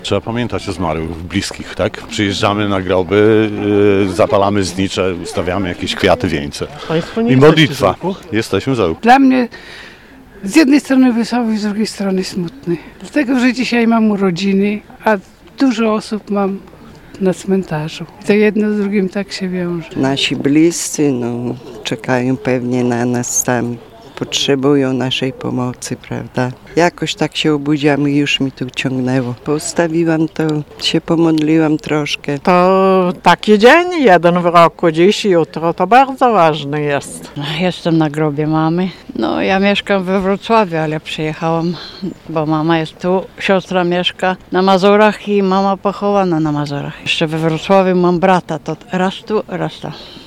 Byliśmy na cmentarzu w Ełku. Rozmawialiśmy o pamięci, zadumie, o waszych przeżyciach.